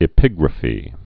(ĭ-pĭgrə-fē)